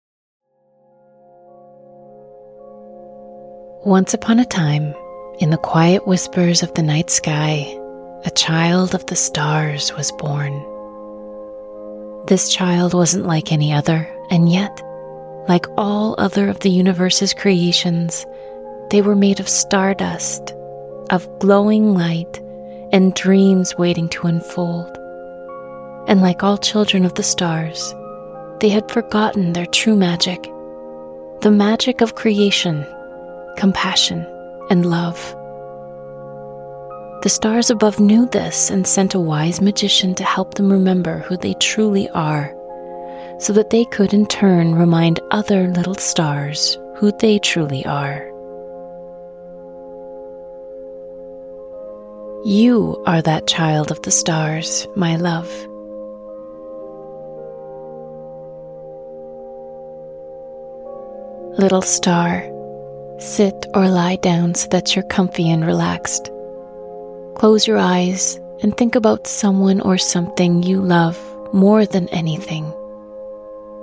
The Child of the Stars is a magical 10-minute breathwork audio journey, perfect for children and their caregivers, and adults needing a gentle and loving reminder about who they are.
In this story, the Child of the Stars is guided by a wise magician who helps them remember who they truly are—a being made of light, compassion, and limitless potential. Through the soothing rhythm of the “Magic Breath,” little stars will learn to calm their hearts, quiet their minds, and expand their self-esteem, while also gaining tools for self-regulation that they can use in their everyday lives.